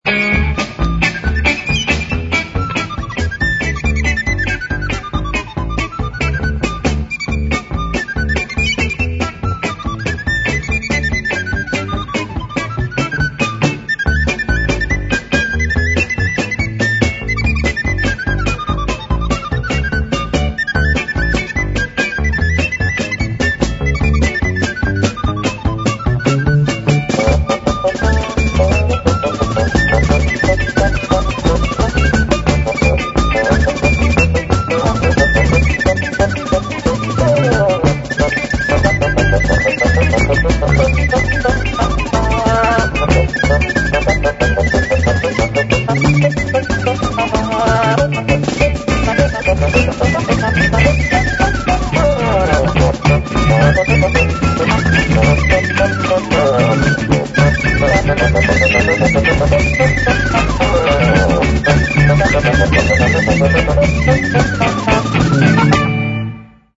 Mono, 1:06, 32 Khz, (file size: 261 Kb).